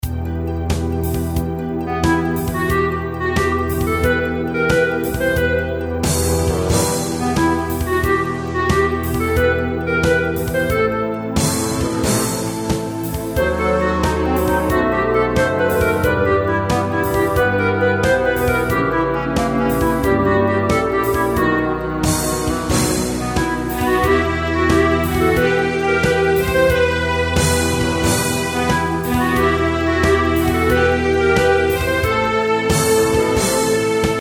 Реквием.